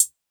kits/OZ/Closed Hats/Hihat (Shit).wav at ts
Hihat (Shit).wav